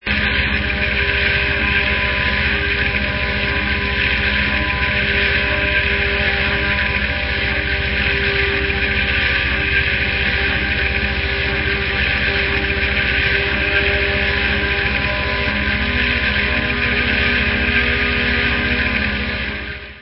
-ULTRA RARE MIND EXPANDING FUZZ FILLED GEMS! -